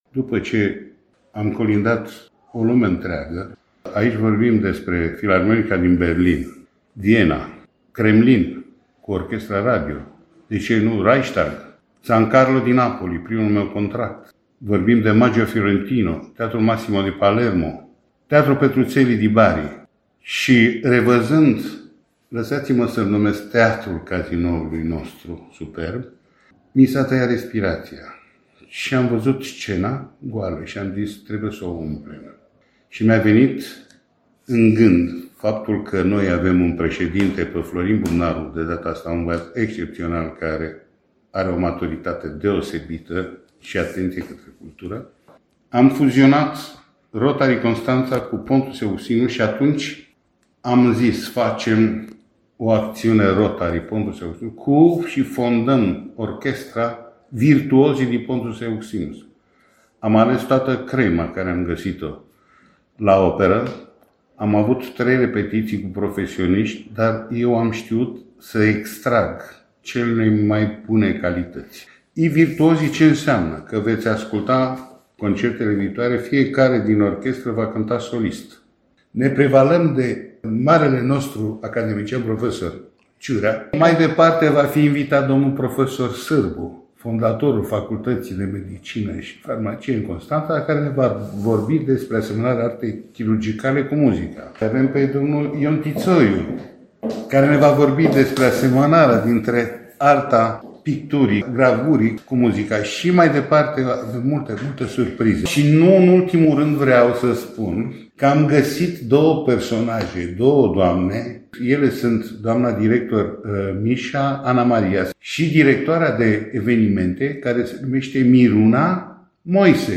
vizibil emoționat